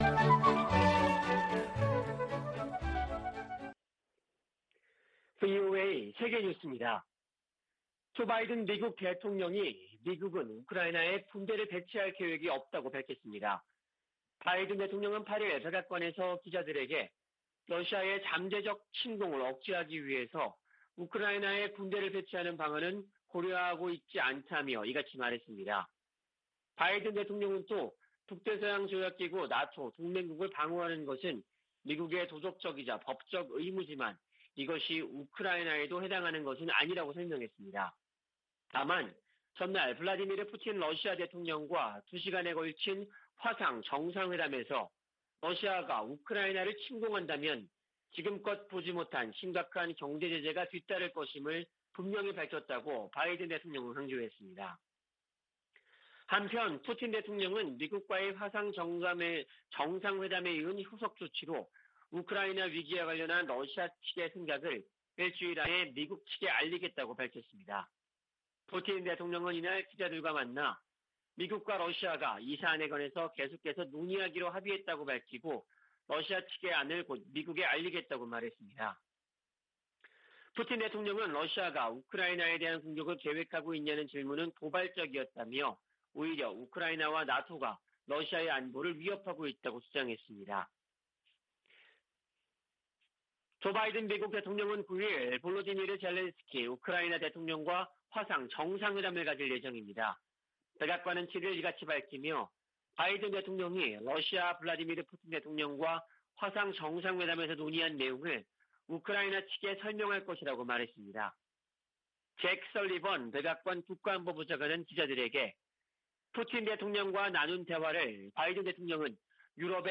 VOA 한국어 아침 뉴스 프로그램 '워싱턴 뉴스 광장' 2021년 12월 9일 방송입니다. 조 바이든 미국 행정부의 ‘외교적 보이콧’으로, 베이징 올림픽 무대를 활용한 한반도 종전선언의 현실화 가능성이 크게 낮아졌습니다. 올림픽 보이콧으로 북한 문제와 관련해 미-중 협력을 기대하기 어렵게 됐다고 전문가들이 지적했습니다.